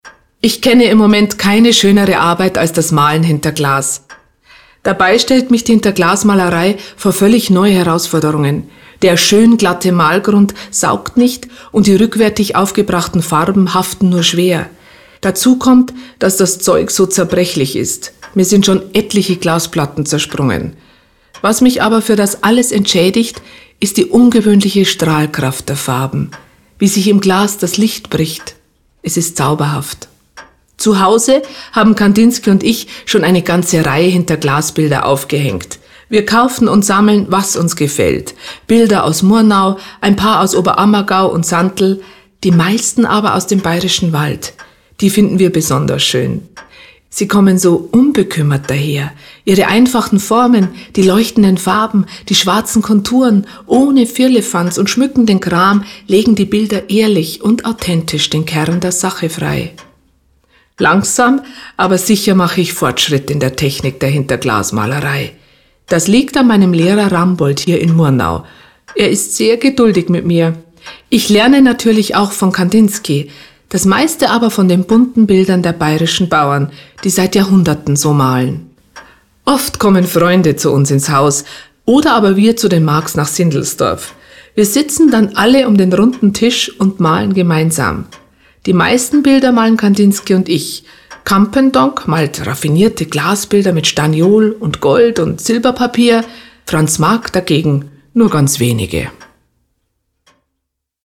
Sprecherin: Luise Kinseher